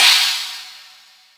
taiko-normal-hitwhistle.wav